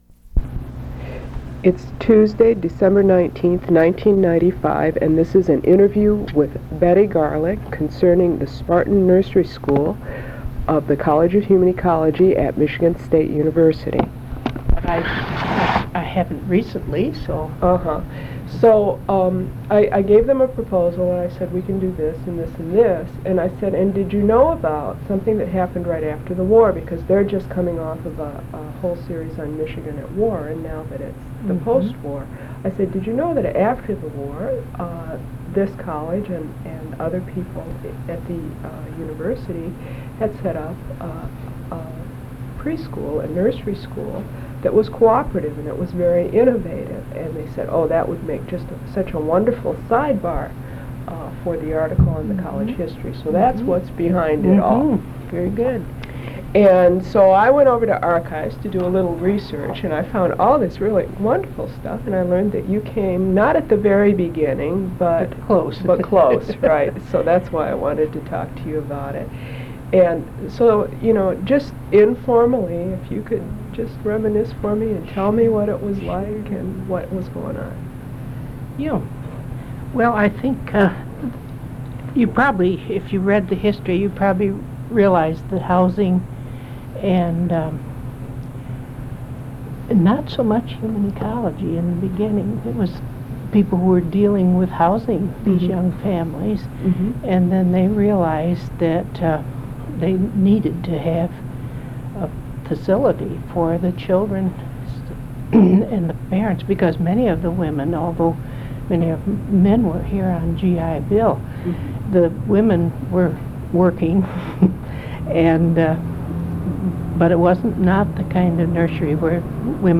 Interview
Original Format: Audio cassette tape